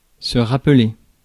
Ääntäminen
France: IPA: [ʁa.ple]